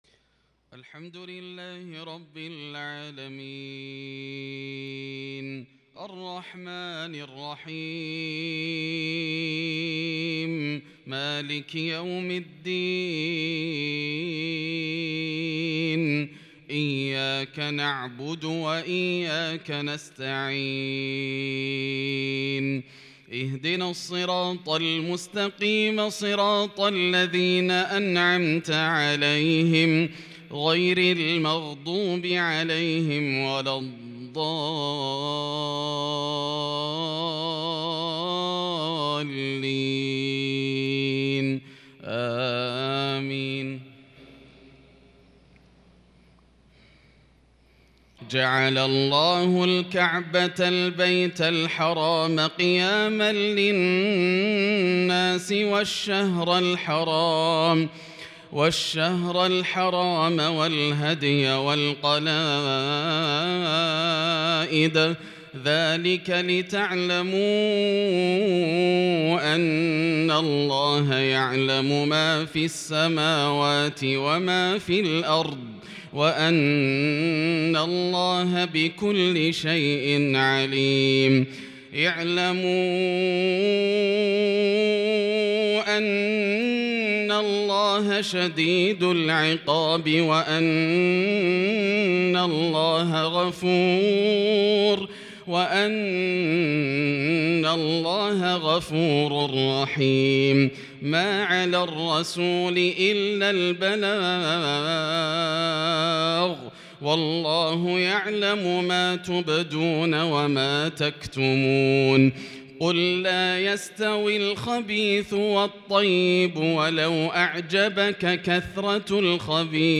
صلاة المغرب للشيخ ياسر الدوسري 8 ذو الحجة 1442 هـ
تِلَاوَات الْحَرَمَيْن .